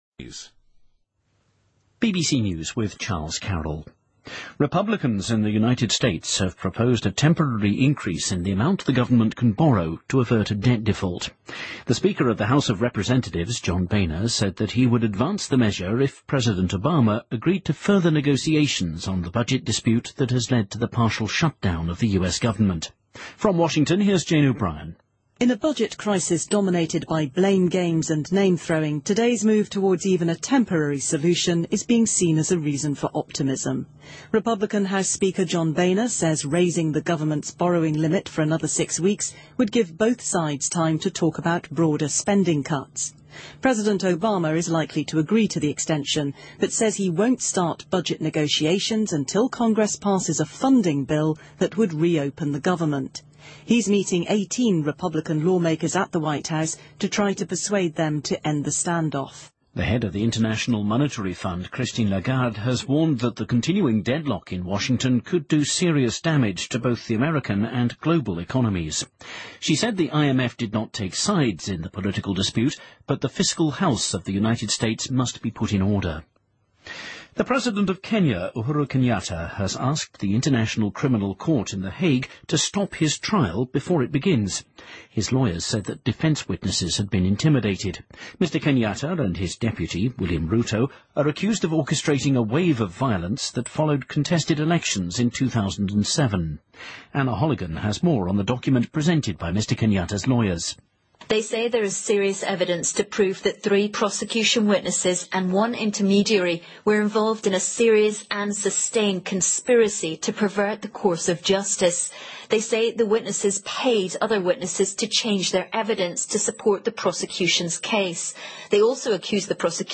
BBC news,美国共和党反对暂时增加政府借债规模以避免债务违约